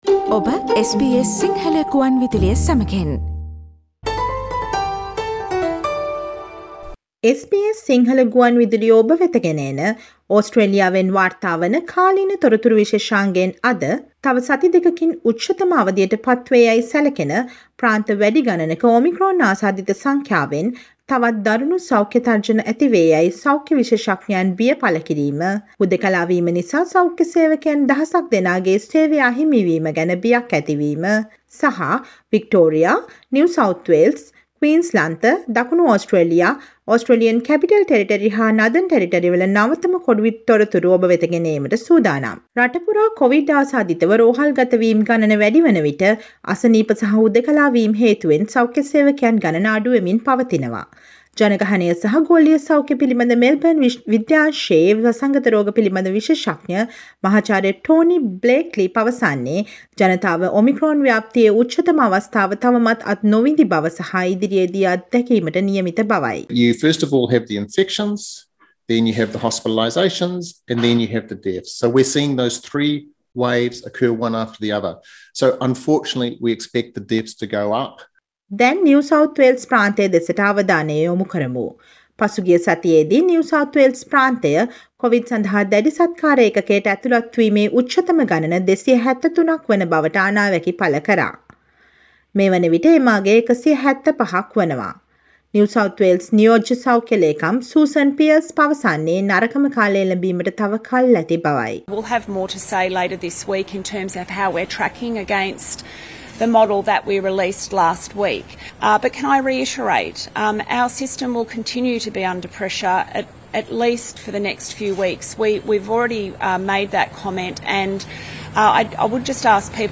Omicron ව්‍යාප්තියේ උපරිමය තවත් සති දෙකකින් පැමිණීම, ඕස්ට්‍රේලියාව පුරා දිනපතා වැඩිවන සෞඛ්‍ය සේවක හිඟය සහ නවතව කොවිඩ් තොරතුරු රැගත් ජනවාරි 13 වෙනි බ්‍රහස්පතින්දා ප්‍රචාරය වූ SBS සිංහල ගුවන්විදුලි සේවයේ කාලීන තොරතුරු ප්‍රචාරයට සවන් දෙන්න.